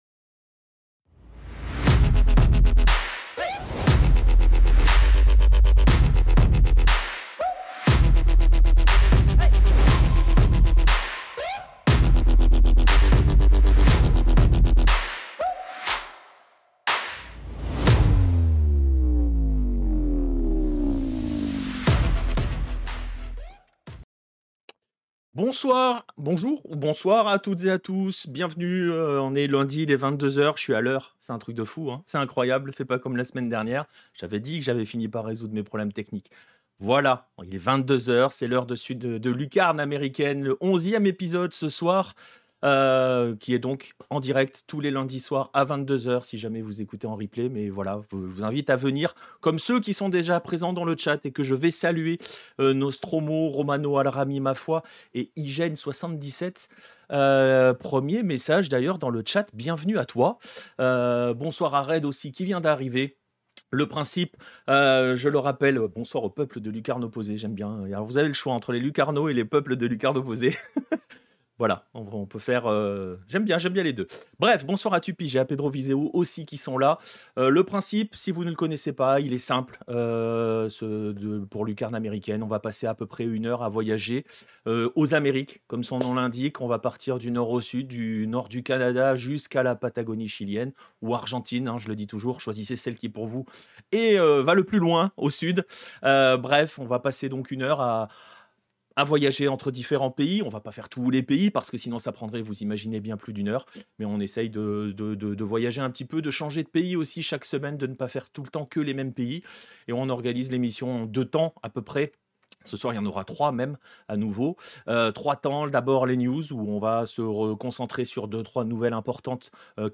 Musique Electronic Future Bass